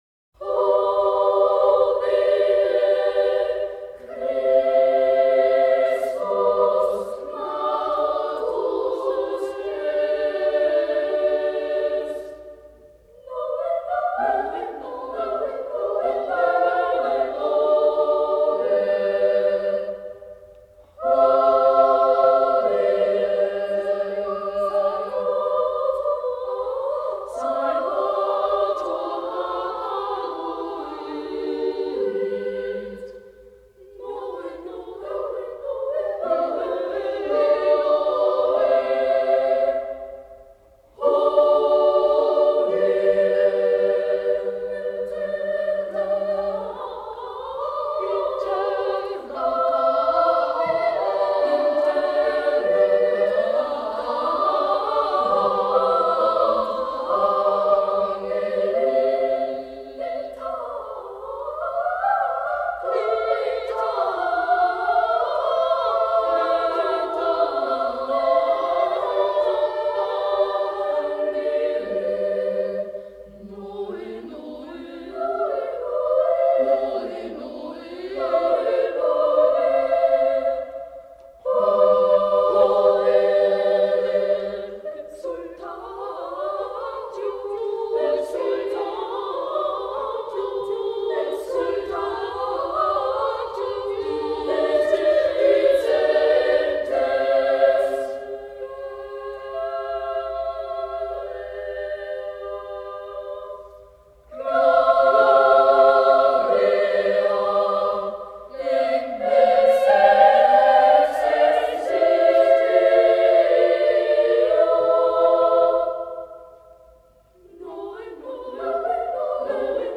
Die Wiener Sängerknaben - 19.12.
Hodie Christus natus est - Heute ist Christus geboren – ist eine mittelalterliche Antiphon zu Weihnachten.
Palestrina macht daraus eine schwungvolle Melodie; immer wieder unterbrochen von jubelnden Weihnachtsrufen: Noe, noe!
Palestrina turns it into a lively melody, repeatedly interrupted by jubilant Christmas shouts: Noe, noe!